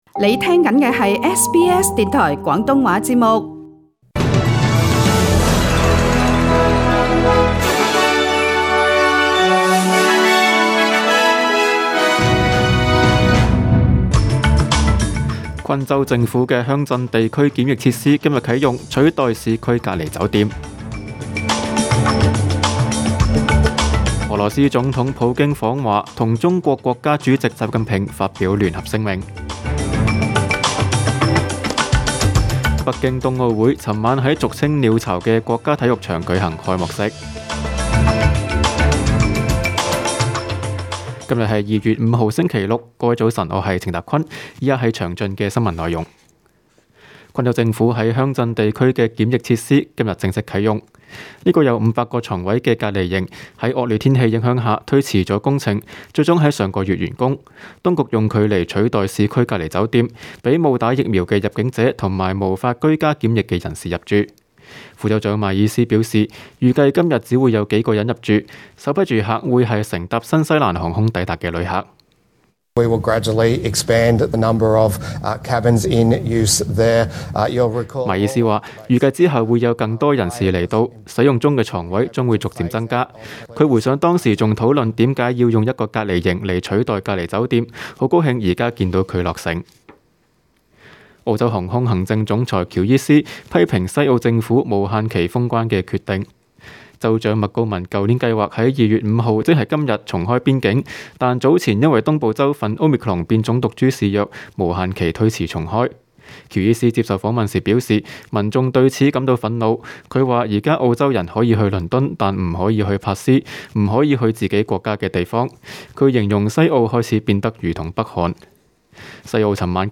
中文新聞 （2月5日）